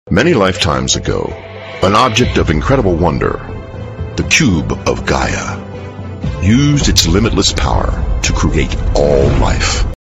特点：大气浑厚 稳重磁性 激情力度 成熟厚重
风格:浑厚配音